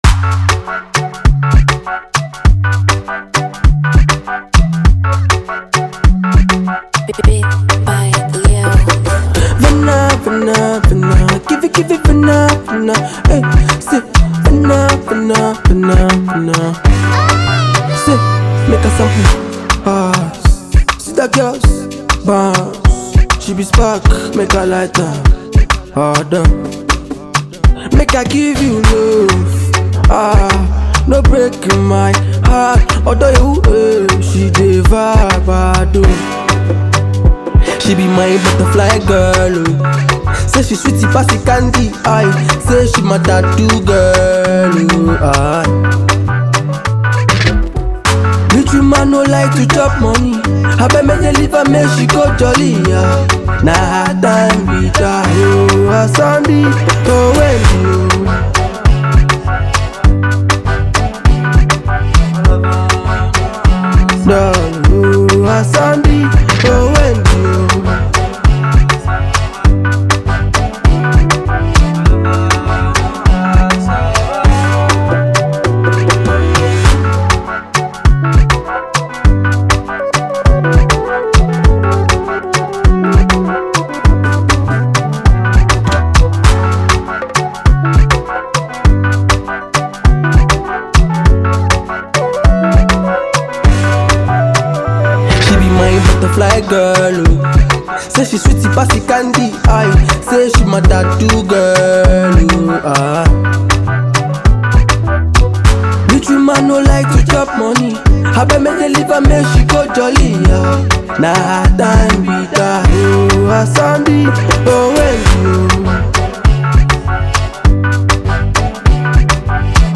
a differently new sound that will make you dance